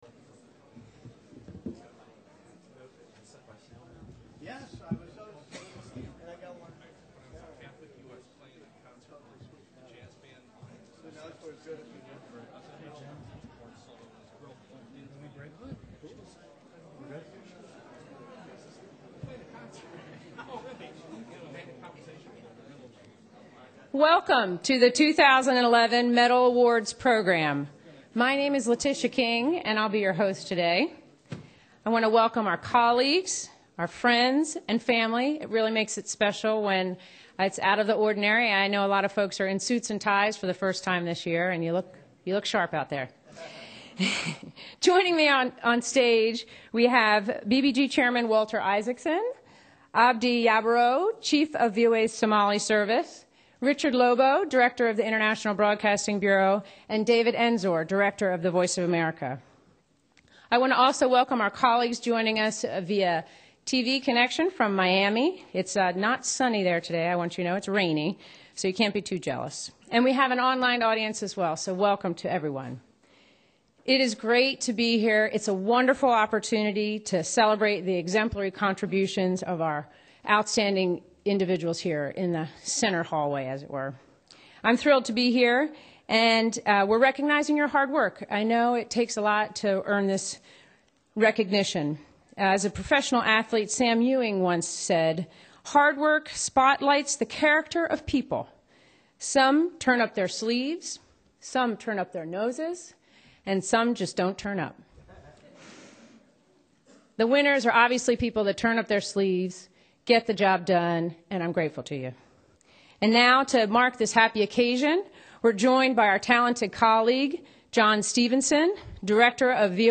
BBG_Awards_Ceremony.mp3